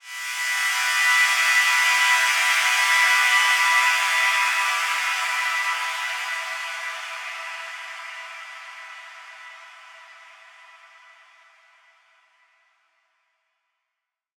SaS_HiFilterPad06-E.wav